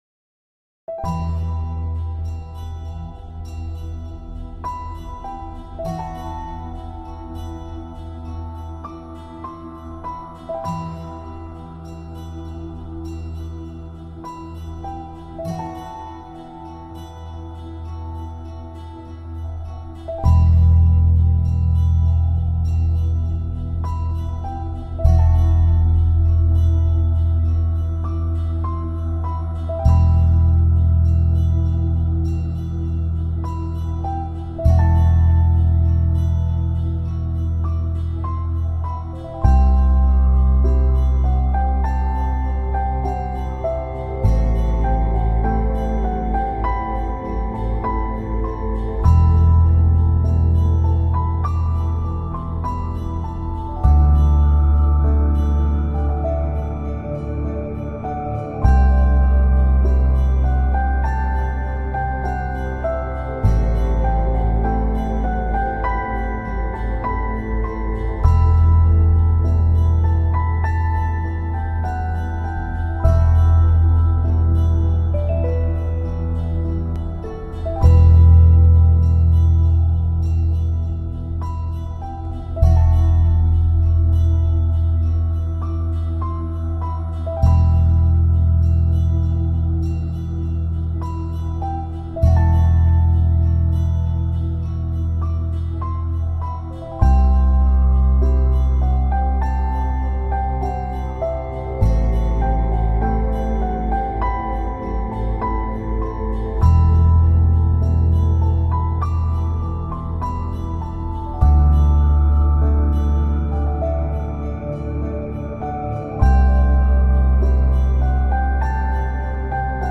tema dizi müziği, duygusal hüzünlü üzgün fon müzik.